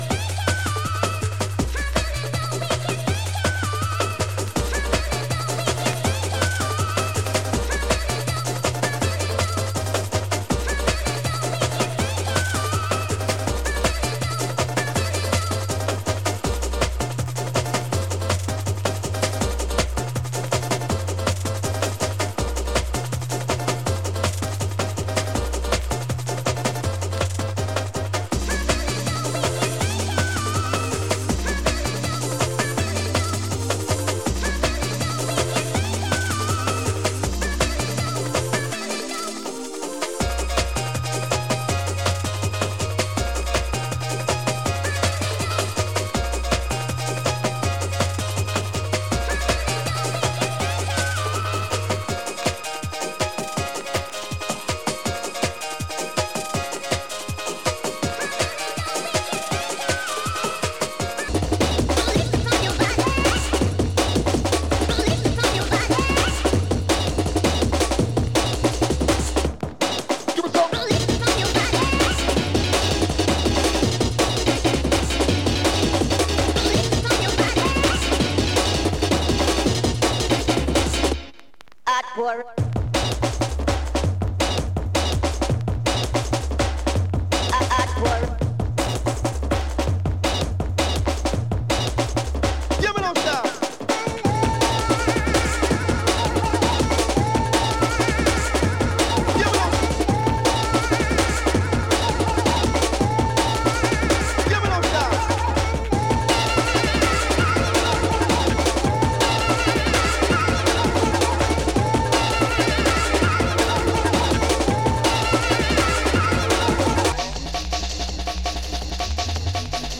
Original 1993 pressing